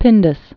(pĭndəs)